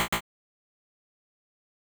error can't put down creature.wav